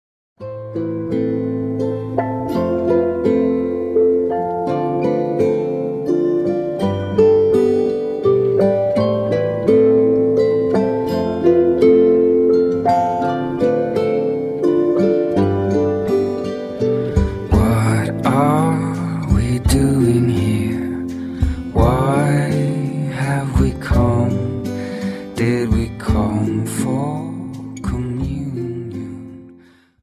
Slow Waltz 28 Song